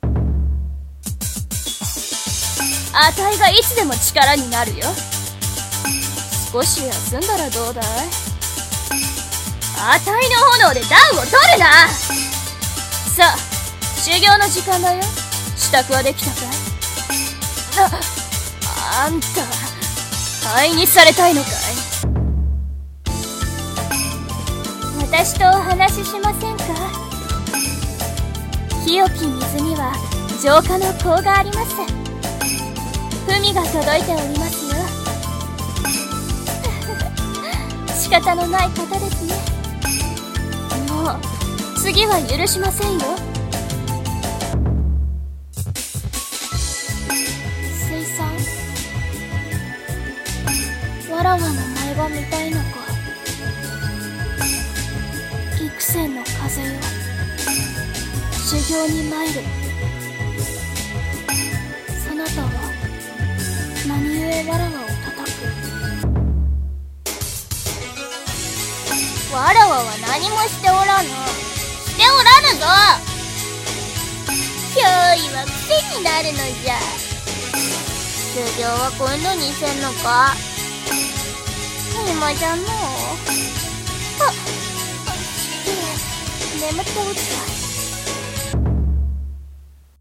【演じ分け台本】
女声/少年声用